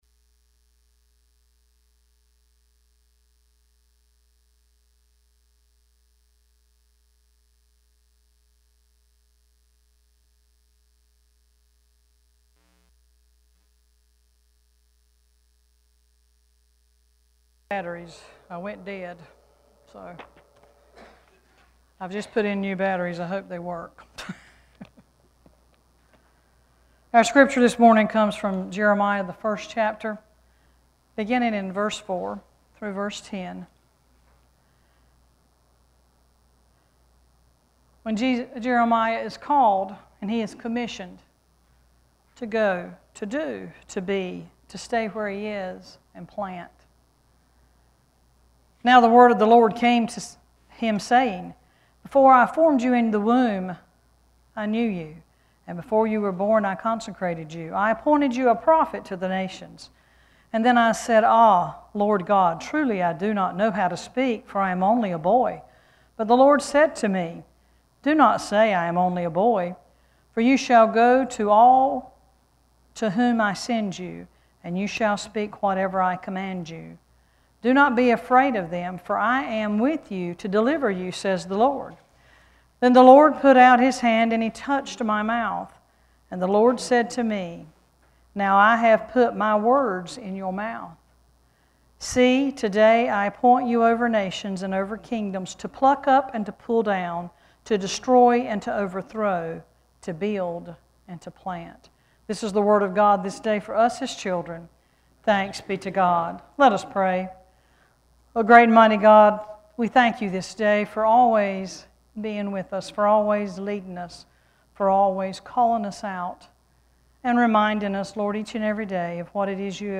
Worship Service 8-21-16: “Call and Sent Forth”
8-21-scripture.mp3